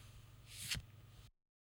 効果音
少し効果音を録音しました。
カードを引く2